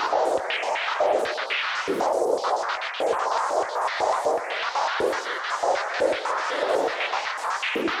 Index of /musicradar/stereo-toolkit-samples/Tempo Loops/120bpm
STK_MovingNoiseD-120_01.wav